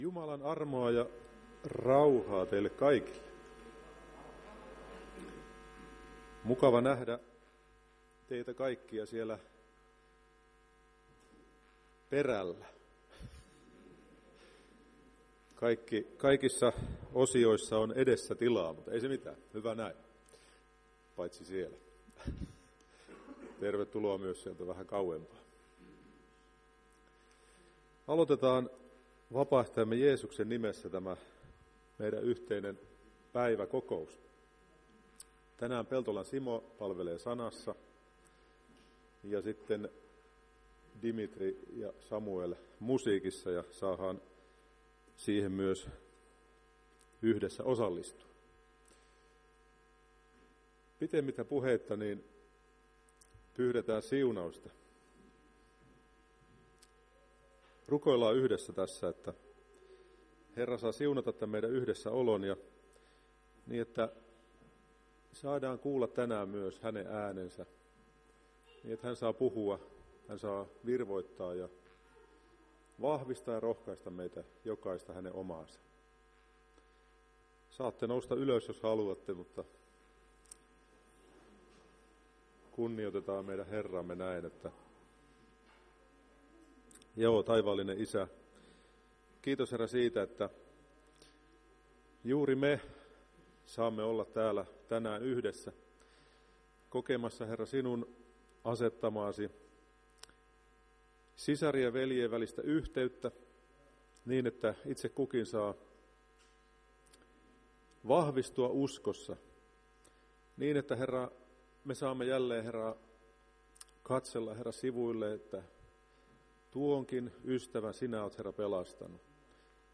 Päiväkokous 11.9.2022